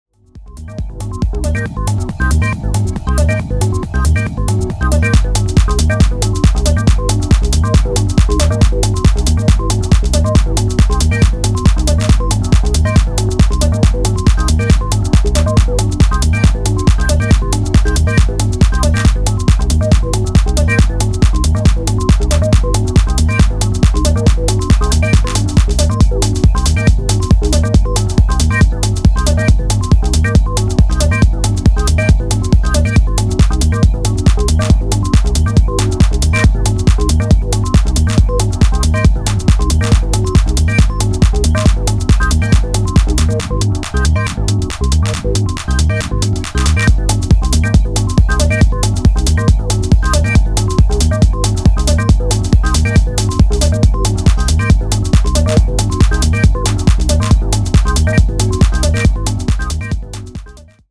どちらもシンプルでフロア訴求力抜群な仕上がりが推せます！